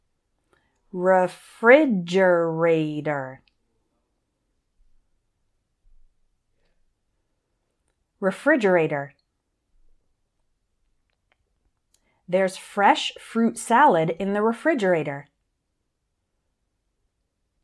I’ll say each one once slowly, once at normal speed, and then I’ll say an example sentence – so listen and repeat after me each time.